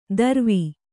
♪ darvi